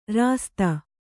♪ rāsta